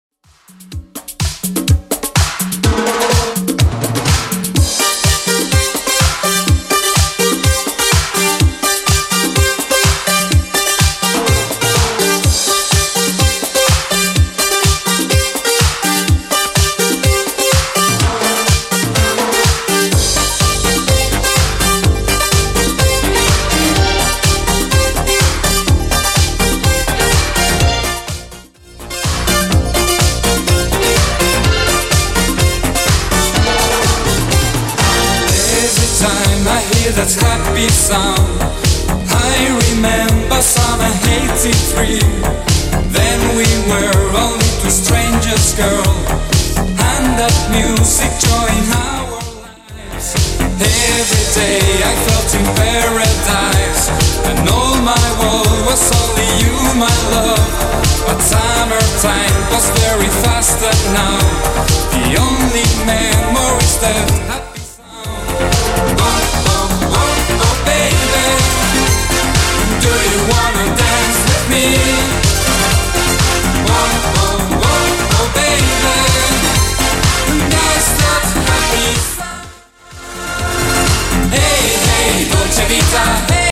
Genre: 70's Version: Clean BPM: 88